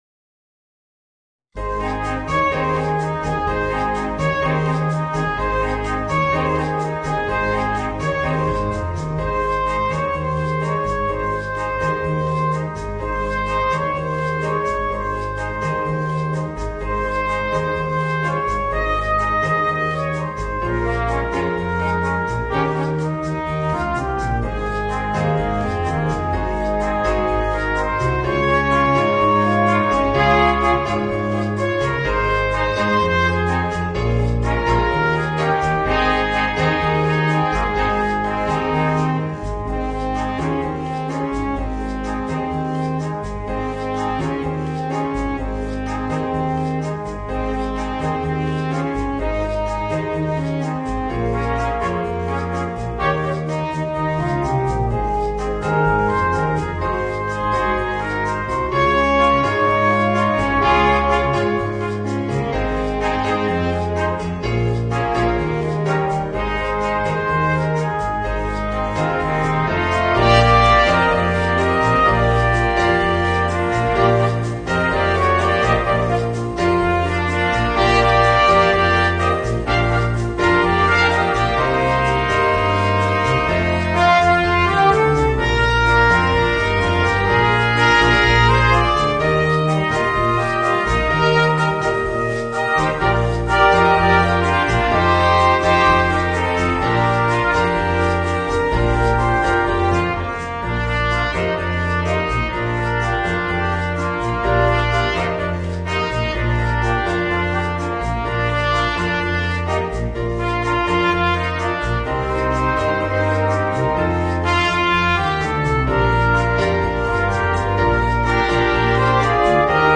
Voicing: 4 - Part Ensemble and Piano